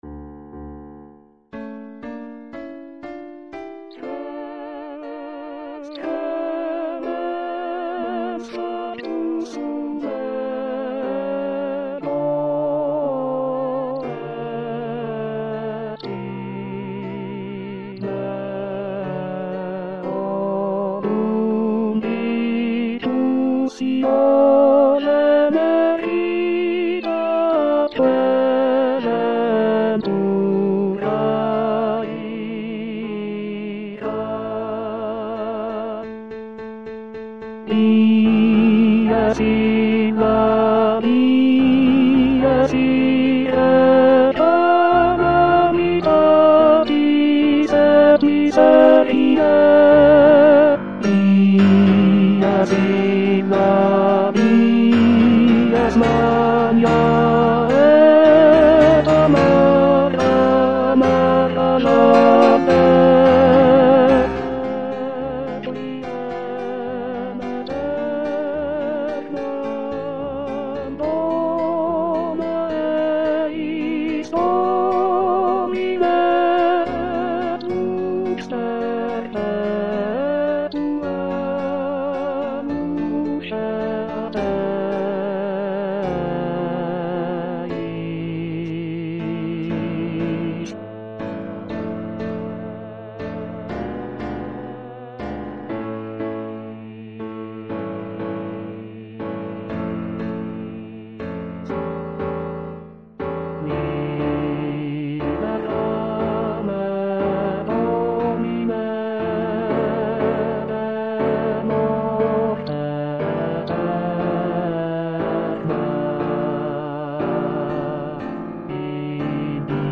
ATTENTION : ces fichiers audio comportent peu ou pas de nuances, il ne s'agit (normalement!) que des bonnes notes à la bonne place
avec la bonne durée chantées par des voix synthétiques plus ou moins agréables .